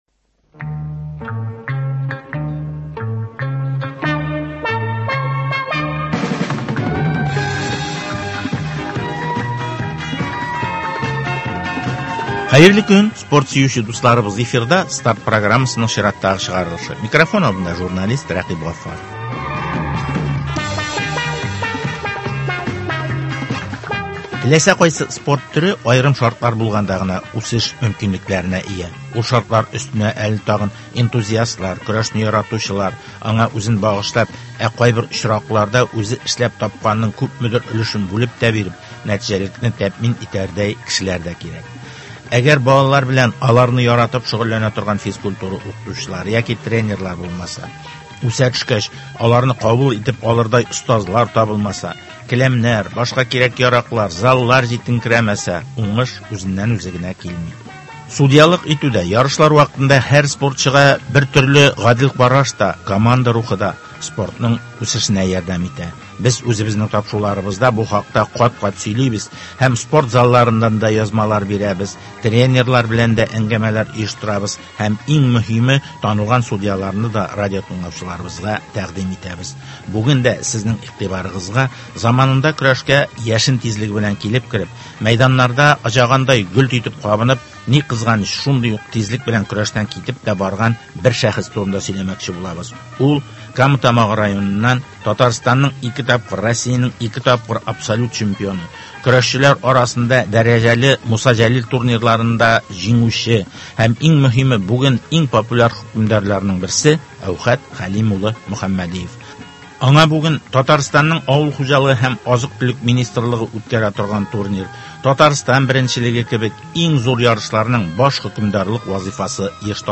Беседа с легендой мирового лыжного спорта Александром Завьяловым о развитии лыжного спорта в России и в Татарстане.